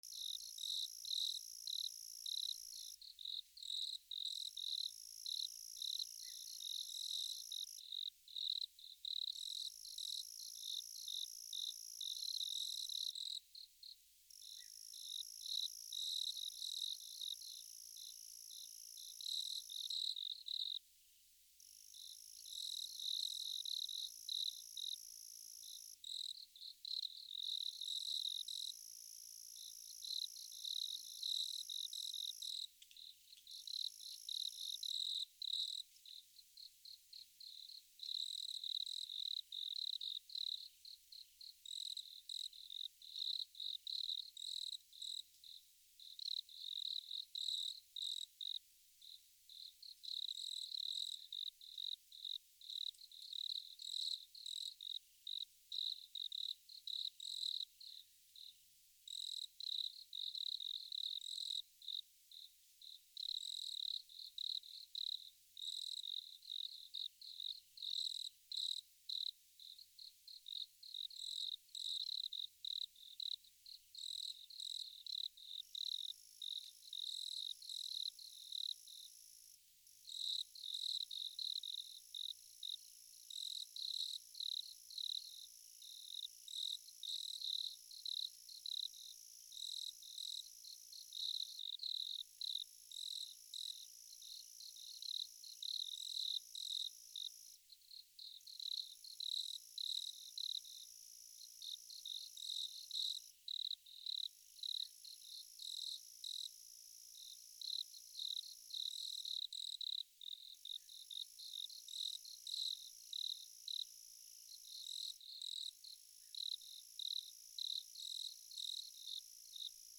虫の鳴き声 色々 リーー
/ B｜環境音(自然) / B-30 ｜虫の鳴き声 / 虫20_虫の鳴き声20_山・森林
原音あり NT4 高野山